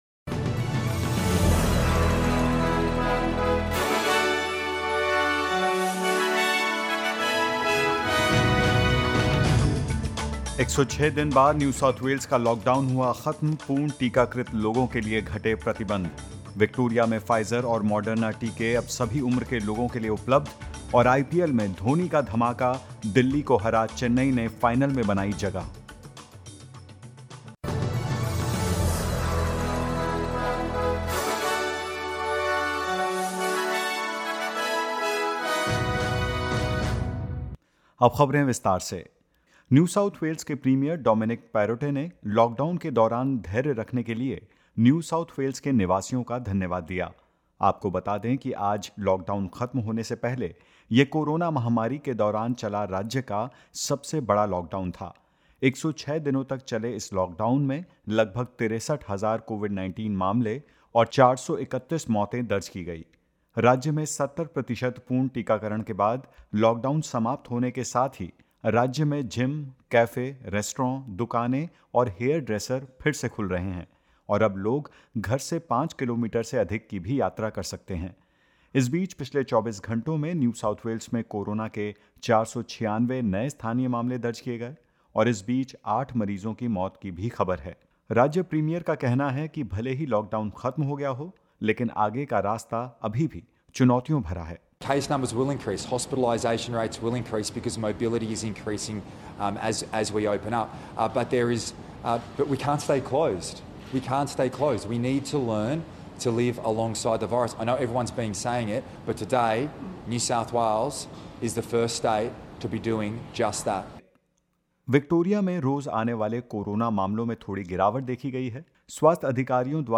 In this latest SBS Hindi News bulletin of Australia and India: NSW reopens with a word of caution from the Premier; New South Wales recorded 496 new locally acquired cases and eight deaths and more.